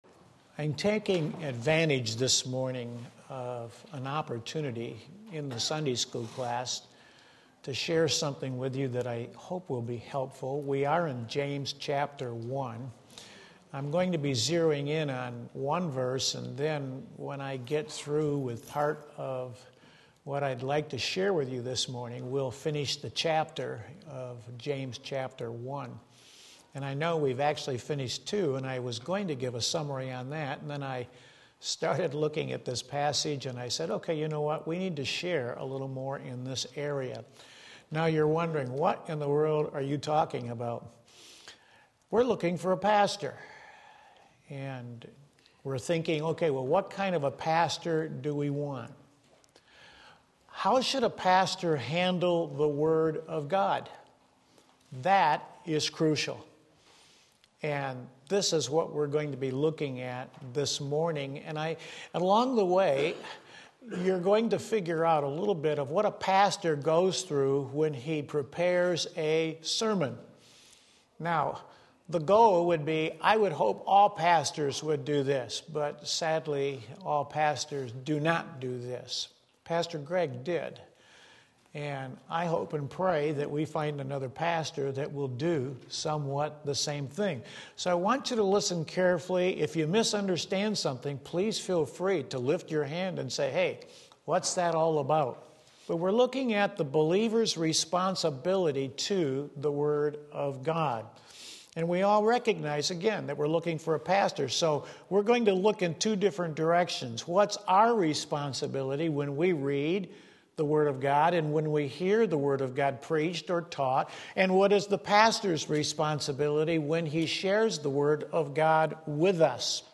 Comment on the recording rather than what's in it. The Believer's Responsibility to the Word of God James 1:19-22 Sunday School